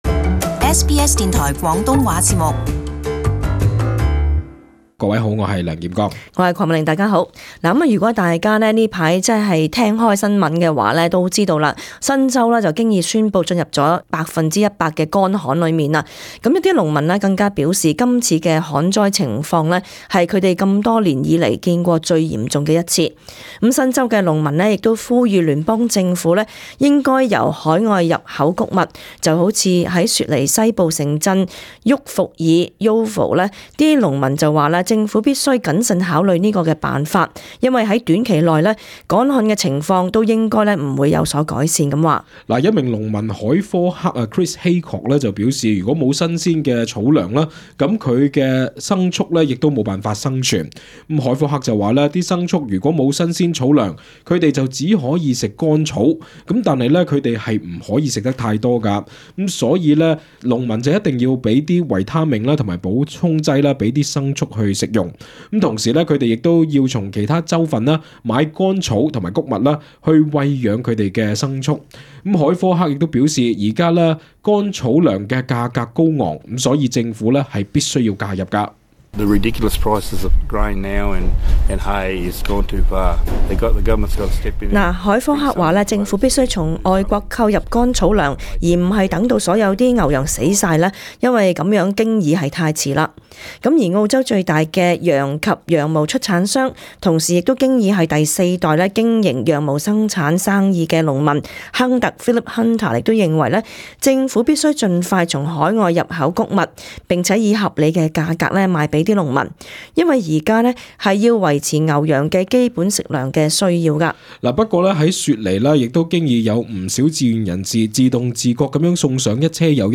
【時事報導】受旱災影響農民籲政府協助進口飼料